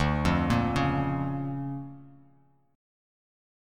DmM7#5 Chord
Listen to DmM7#5 strummed